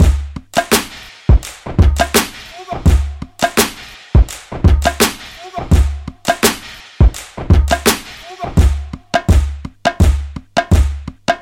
康加鼓
Tag: 84 bpm Hip Hop Loops Drum Loops 1.92 MB wav Key : Unknown